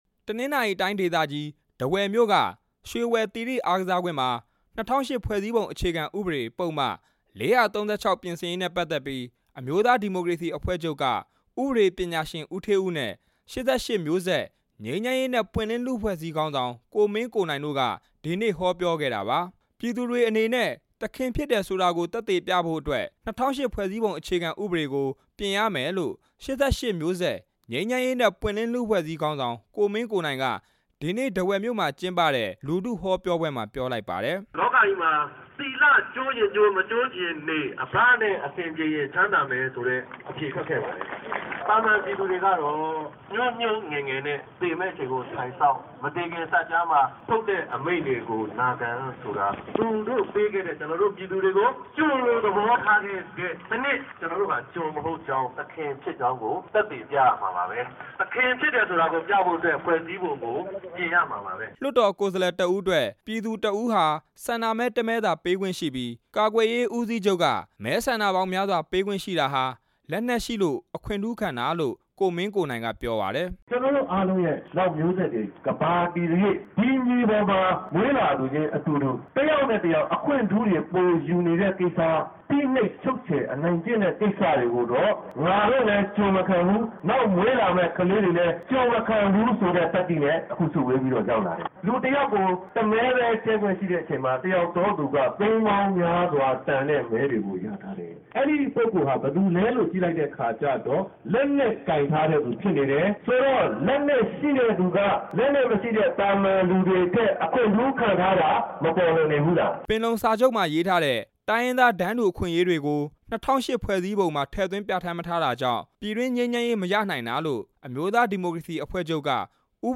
တနင်္သာရီတိုင်း ထားဝယ်မြို့ ရွှေဝယ်သီရိအားကစားကွင်းမှာ အမျိုးသားဒီမိုကရေစီအဖွဲ့ချုပ်နဲ့ ၈၈ မျိုးဆက် ငြိမ်းချမ်းရေးနဲ့ ပွင့်လင်းလူ့အဖွဲ့အစည်းတို့ ပူးပေါင်းကျင်းပတဲ့ ၂၀၀၈ ဖွဲ့စည်းပုံအခြေခံဥပဒေ ပုဒ်မ ၄၃၆ ပြင်ဆင်ရေး လူထုဟောပြောပွဲမှာ ကိုမင်းကိုနိုင်က ပြောခဲ့တာပါ။